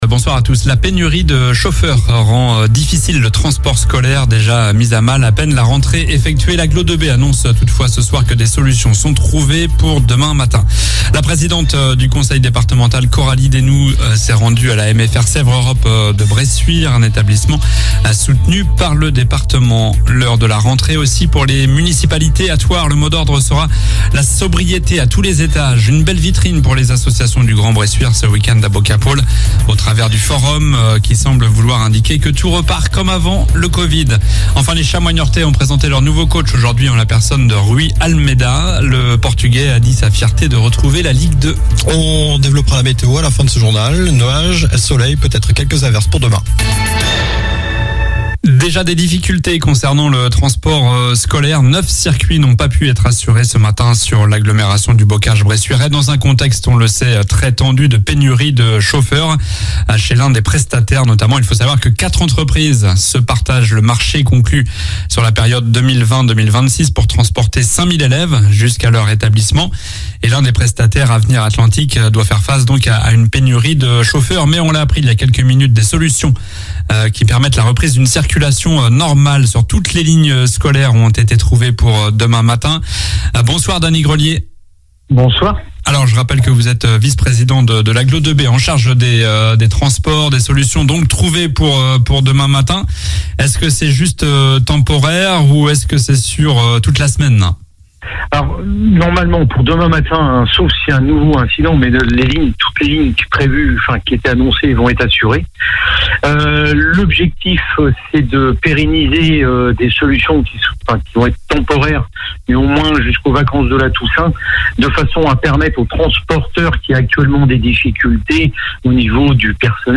Journal du lundi 05 septembre (soir)